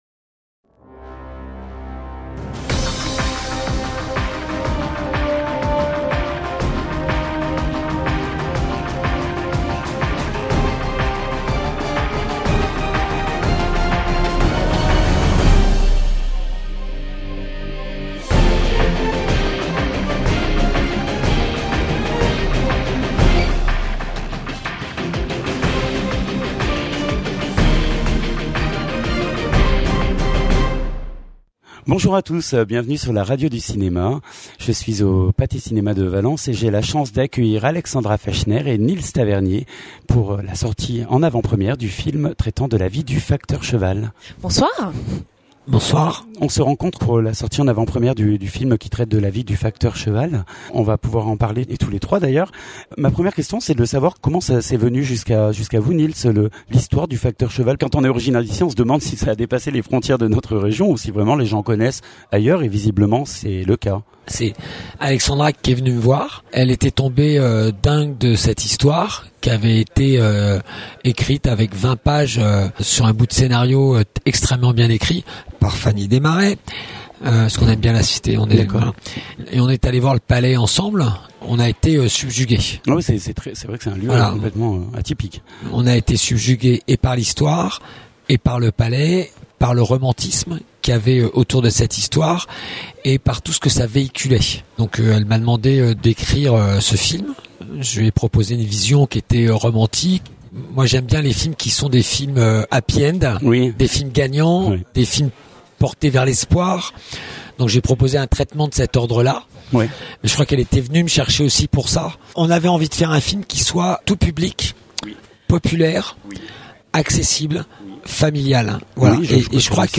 Au cinéma Pathé Valence